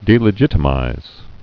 (dēlə-jĭtə-mīz)